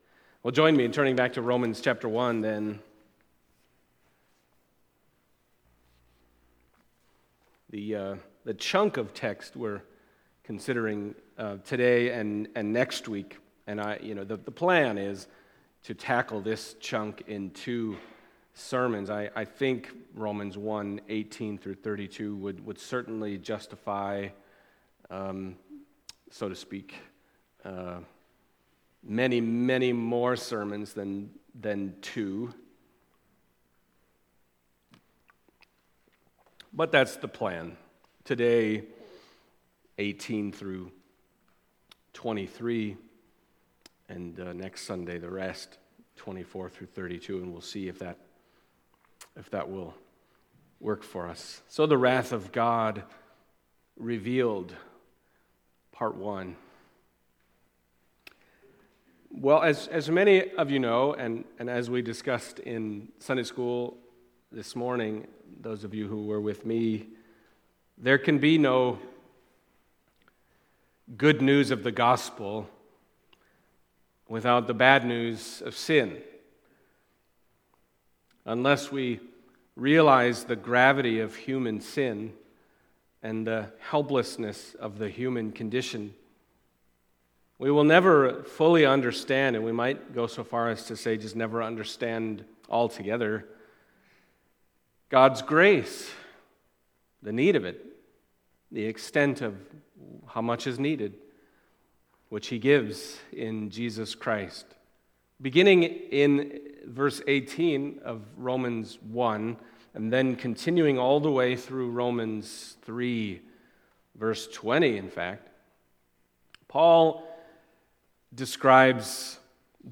Romans Passage: Romans 1:18-32 Service Type: Sunday Morning Romans 1:18-32 « The Righteousness of God The Wrath of God Revealed